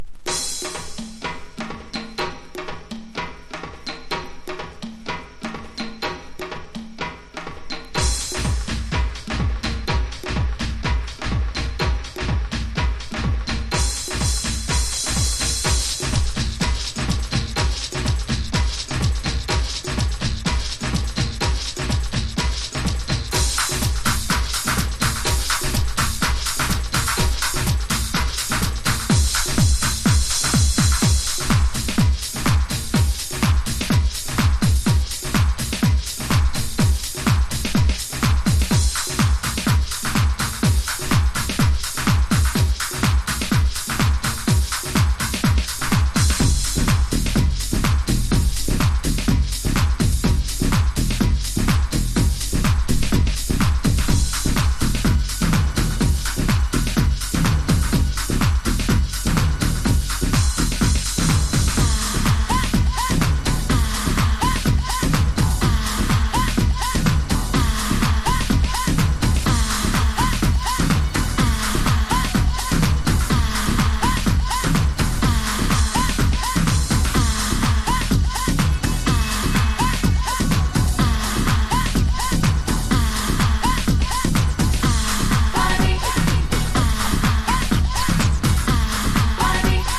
POP CLUB / REMIX
所によりノイズありますが、リスニング用としては問題く、中古盤として標準的なコンディション。